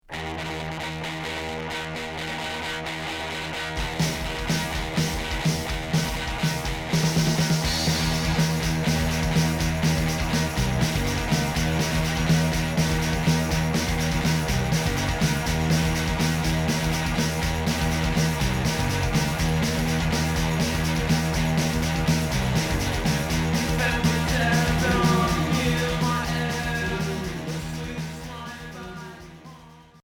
Rock garage punk